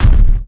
enemy_footStep.wav